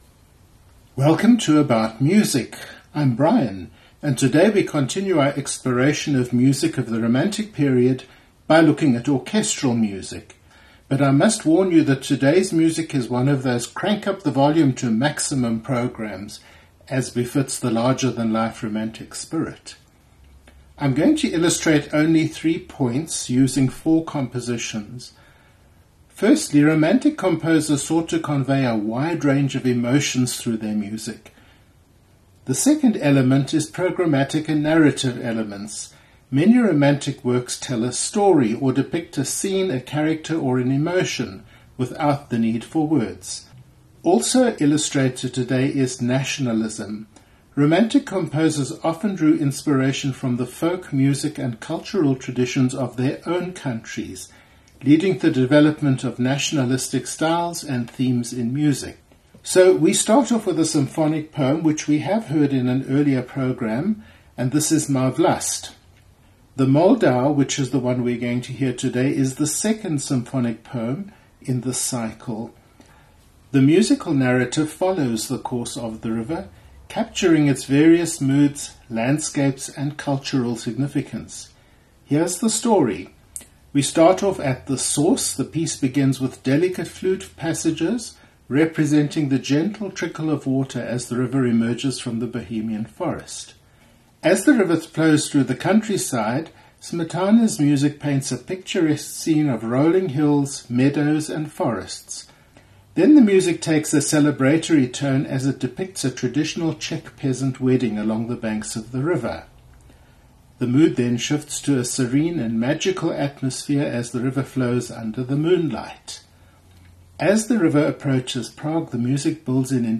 Programme 47: about Music- The Art of Listening: Romantic Orchestral Music - Gay SA Radio
We start off with the Symphonic Poem “The Moldau,” by Bedřich Smetana
tone poem
Bedřich Smetana: Má Vlast, JB1:112 no 2, Vitava Performed by the Wiener Philharmoniker, conducted by Herbert von Karajan · Jean Sibelius: Finlandia Opus 26 no 7
Performed by the Berliner Philharmoniker, conducted by Herbert von Karajan